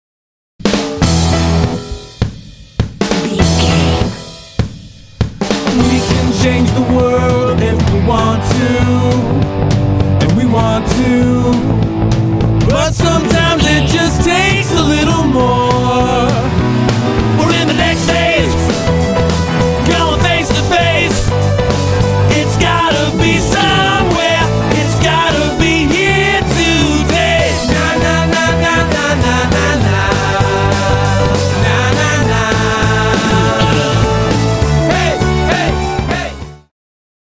Uplifting
Ionian/Major
driving
energetic
drums
electric guitar
bass guitar
vocals
rock
alternative rock
indie